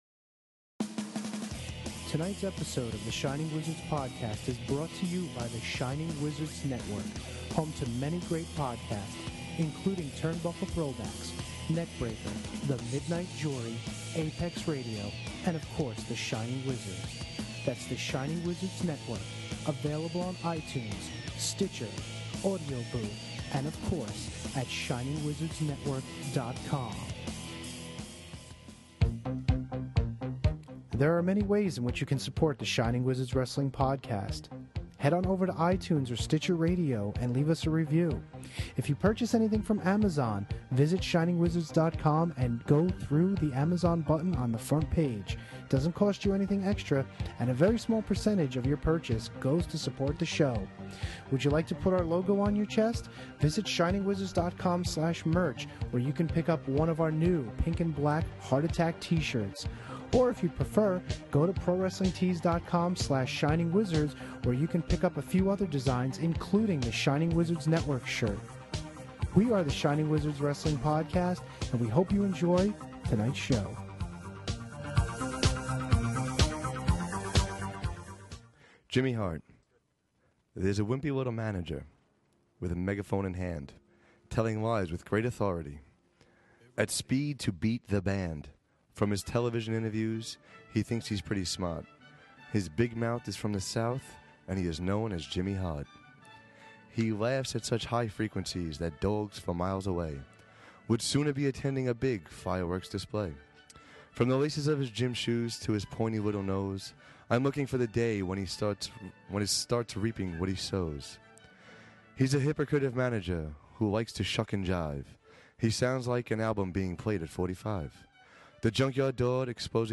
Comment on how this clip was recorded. The boys perform live at Crossroads Comedy Club, May 29, 2014, with special guests the Heavenly Bodies.